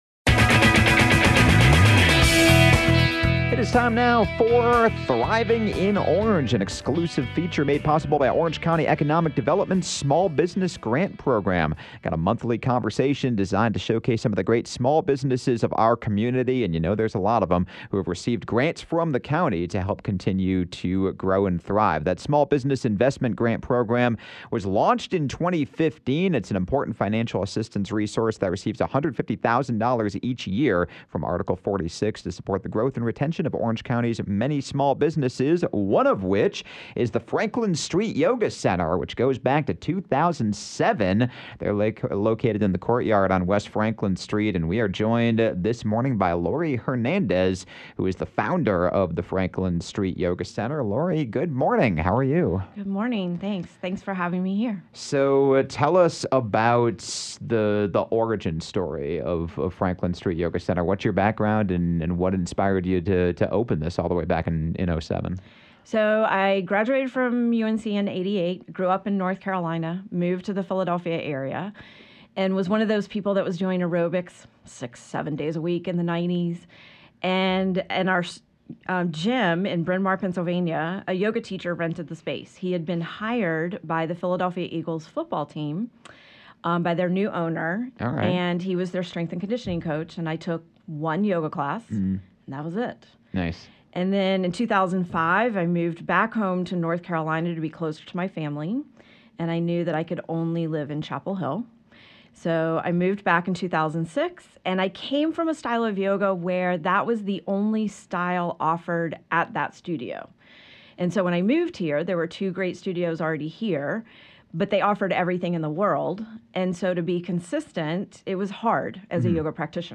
A monthly segment presented by Orange County Economic Development, “Thriving in Orange” features conversations with local business owners about what it’s like to live and work in Orange County, especially in light of the county’s small business grant program which launched in 2015 and has helped small businesses and small business owners with well over $100,000 in grants each year!